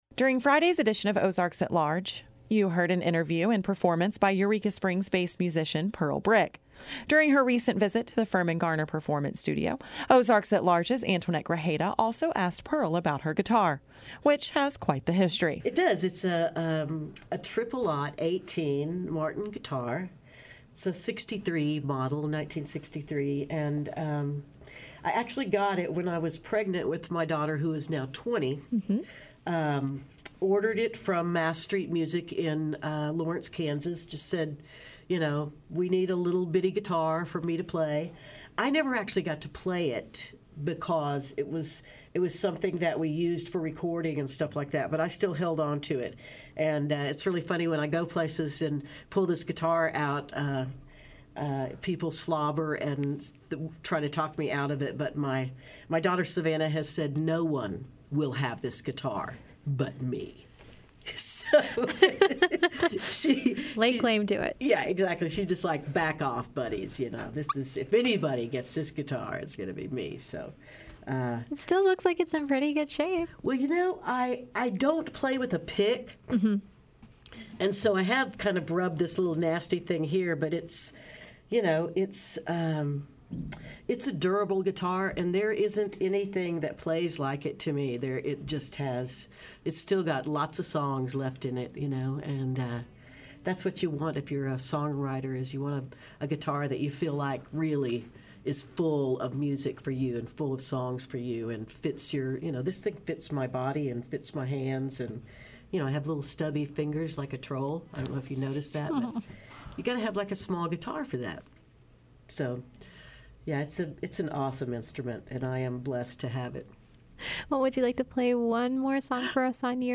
and plays another song in the Firmin-Garner Performance Studio.